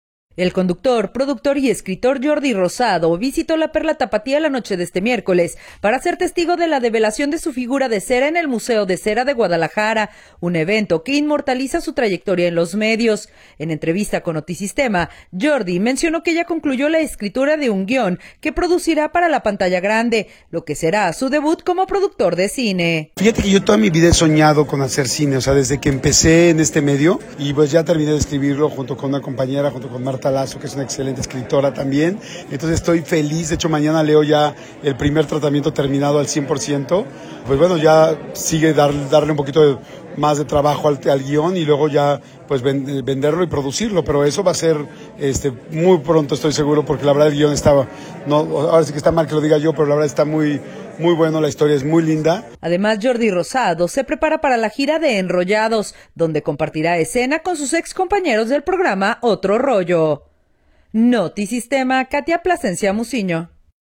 El conductor, productor y escritor Yordi Rosado visitó la Perla Tapatía la noche de este miércoles para ser testigo de la develación de su figura de cera en el Museo de Cera de Guadalajara, un evento que inmortaliza su trayectoria en los medios. En entrevista con Notisistema, Yordi mencionó que ya concluyó la escritura de un guión que producirá para la pantalla grande, lo que será su debut como productor de cine.